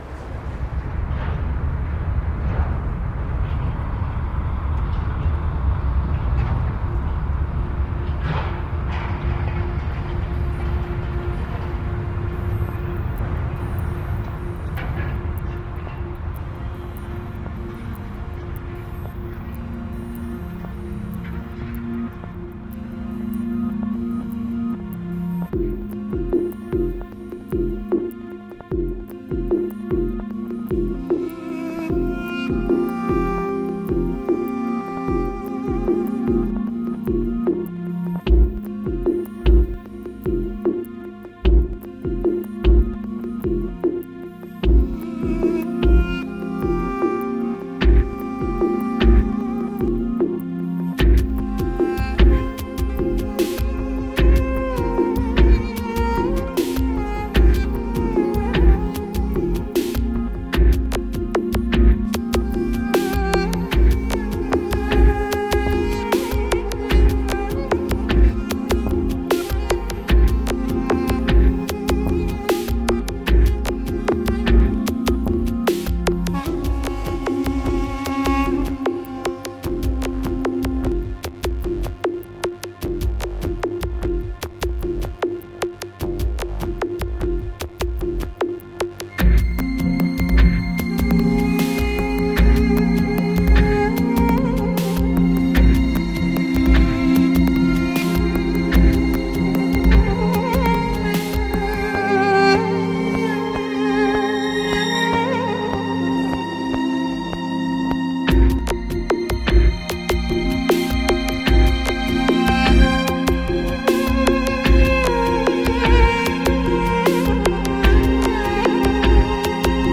★Style: Ambient